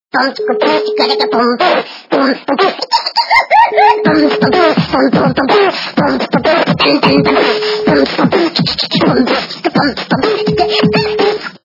Сумасшедший лягушонок - Psycho frog beatbox Звук Звуки Скажене жабеня - Psycho frog beatbox
» Звуки » Люди фразы » Сумасшедший лягушонок - Psycho frog beatbox